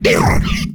attack3.ogg